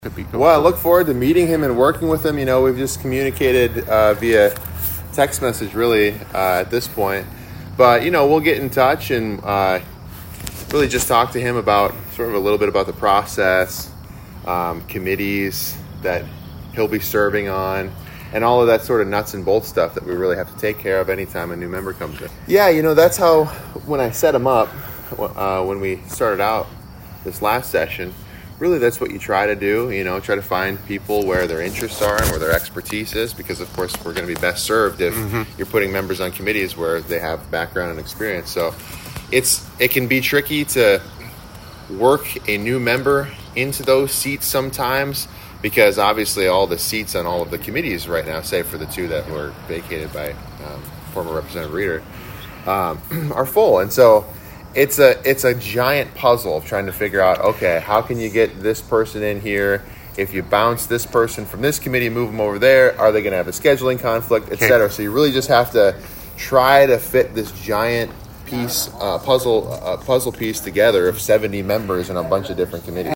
HubCityRadio had an opportunity to do an interview with him to address several different topics.